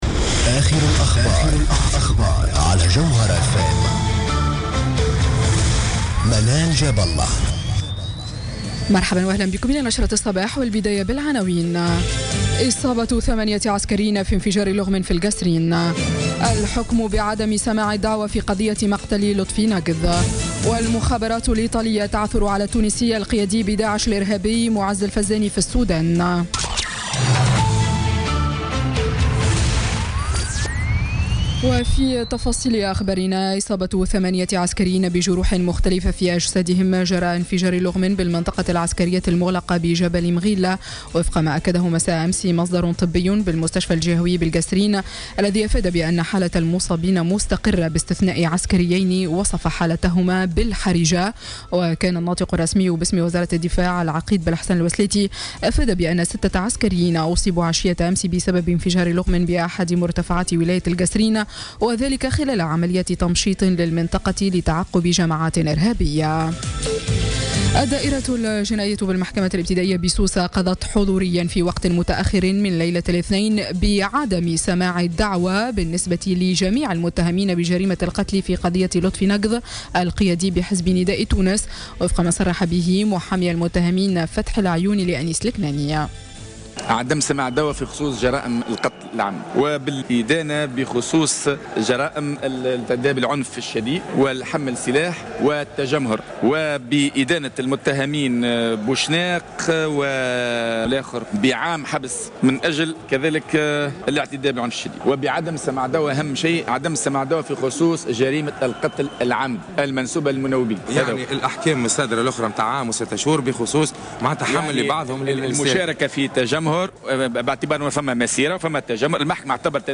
نشرة أخبار السابعة مساء ليوم الثلاثاء 15 نوفمبر 2016